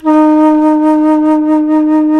FLT ALTO F0Z.wav